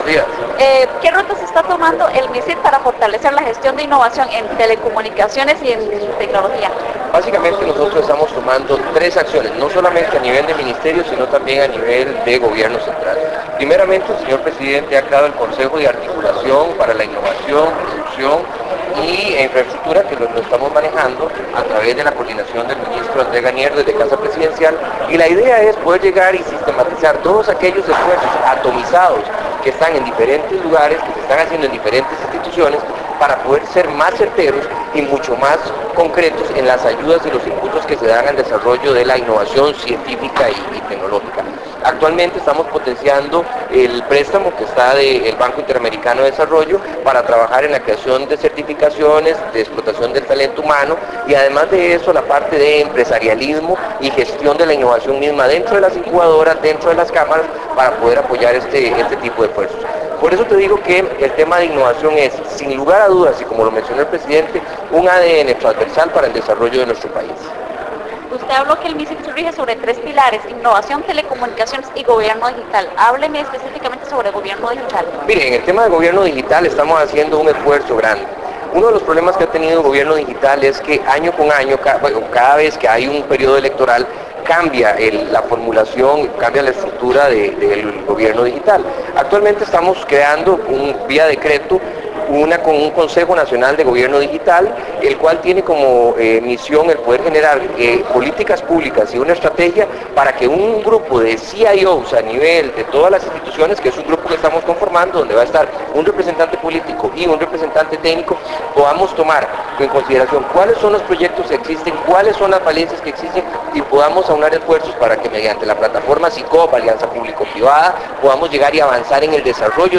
Entrevista al ministro Luis Aadrián Salazar Solís en Lanzamiento del Sistema de Información Nacional de Ciencia y Tecnología (Sincyt) con Esperanza TV